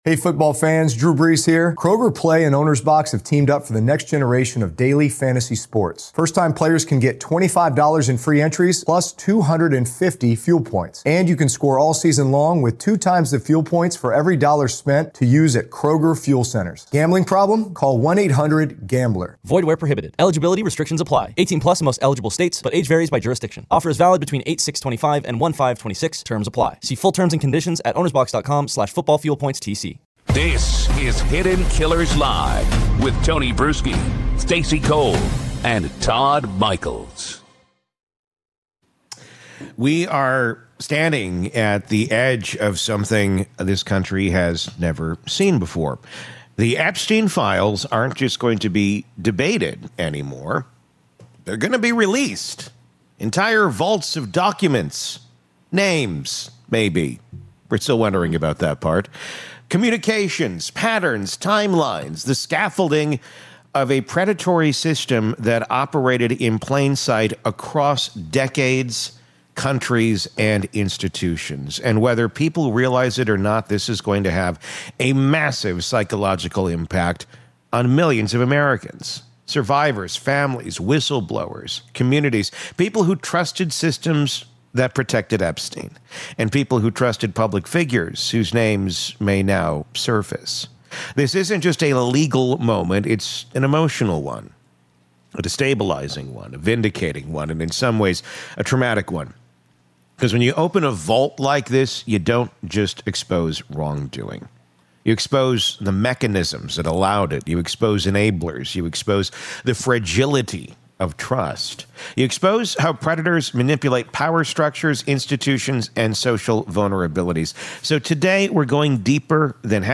In this powerful hour-long conversation